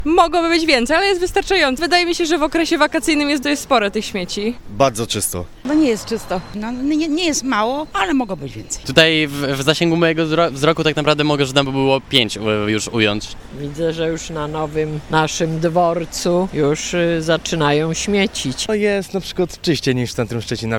Z mikrofonem na ulice Stargardu